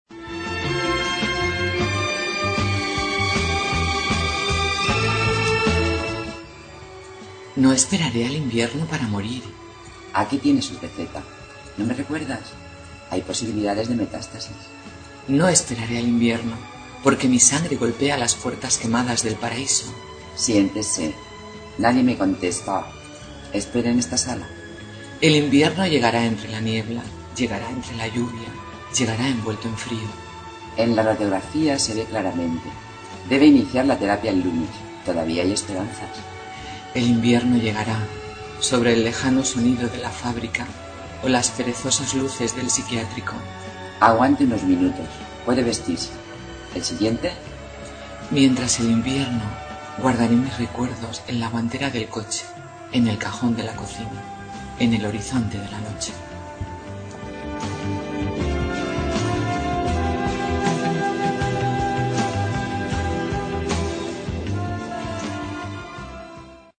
Inicio Multimedia Audiopoemas No esperaré.